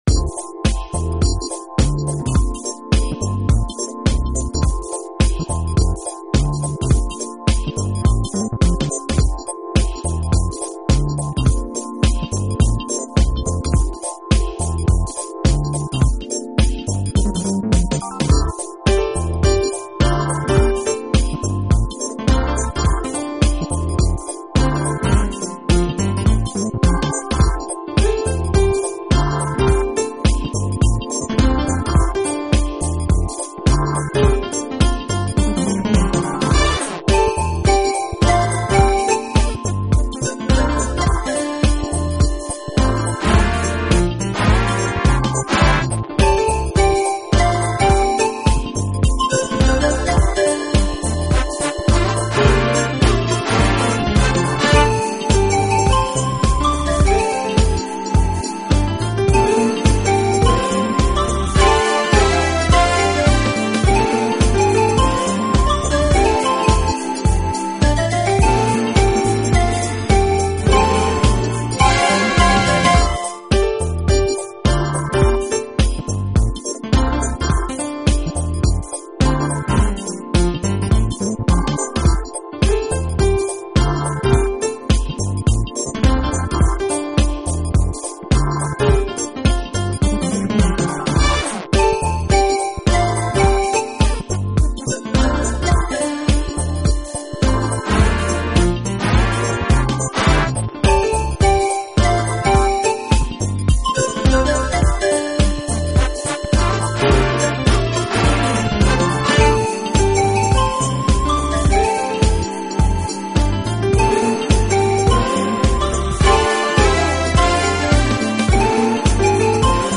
Genre: Smooth Jazz/Jazz/Piano